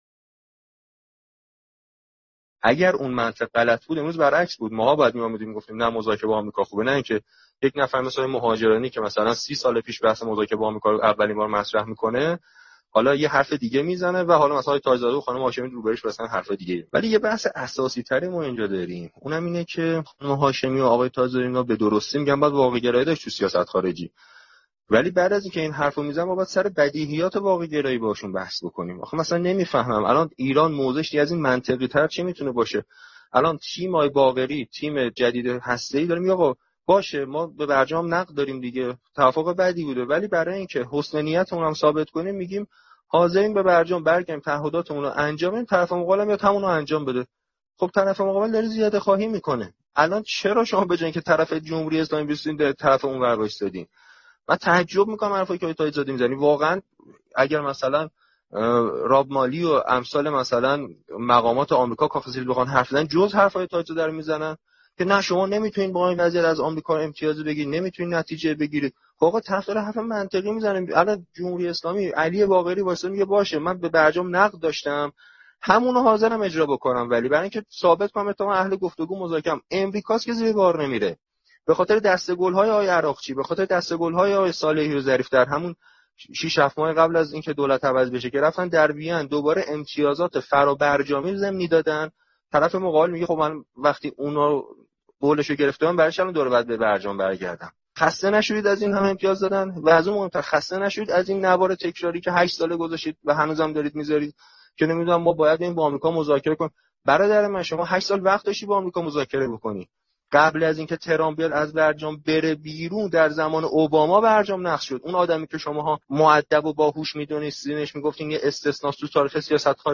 صوت جنجالی امیر حسین ثابتی مقابل تاجزاده و فائزه هاشمی در کلاب هاوس در مورد توافق و رابطه با آمریکا